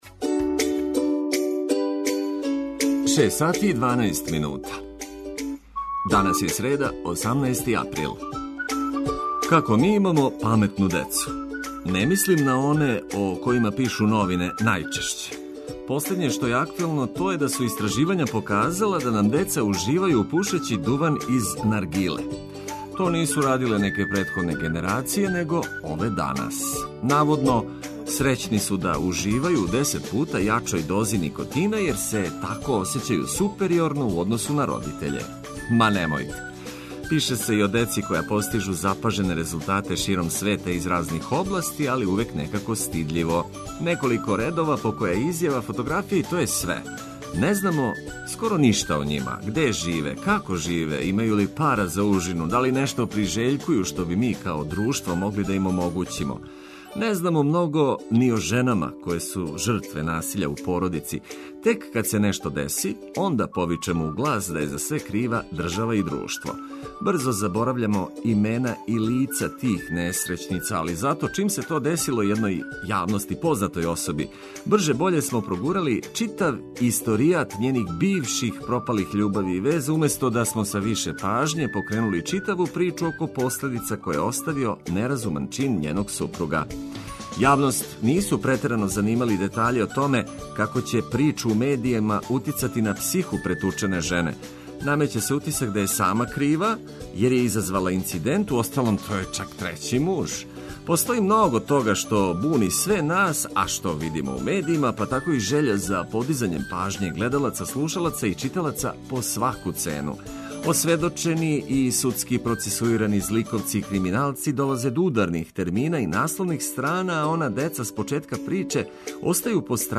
Буђење је пријатније уз музику и корисне информације.